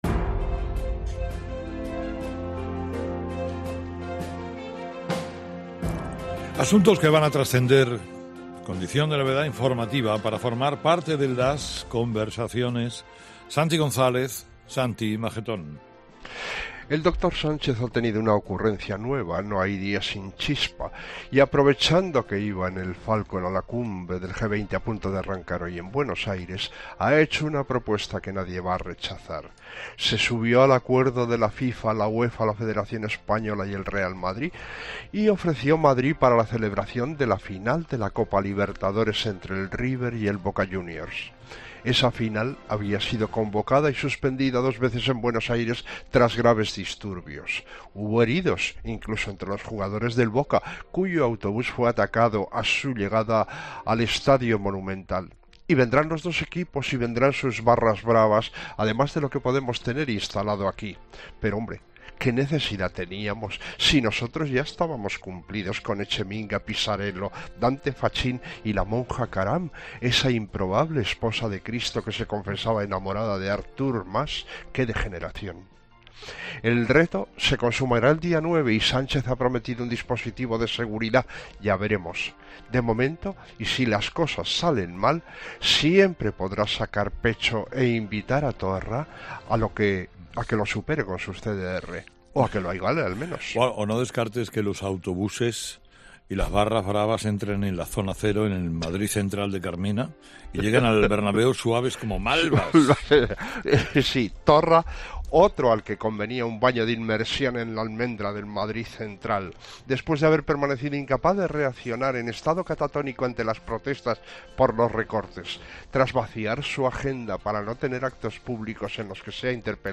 El tertuliano de 'Herrera en COPE' opina sobre que el partido Boca - River se juege en el Bernabéu